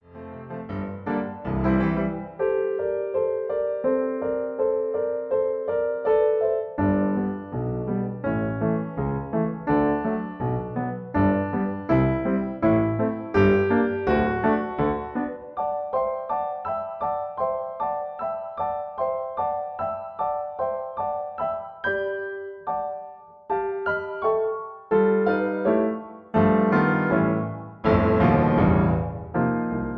In B-flat. Piano accompaniment